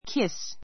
kiss A1 kís キ ス 名詞 キス , 口づけ give her a kiss (on the cheek) give her a kiss ( on the cheek) 彼女（のほお）にキスする 動詞 キスする , 口づけする kiss her on the cheek kiss her on the cheek 彼女のほおにキスする The lovers kissed.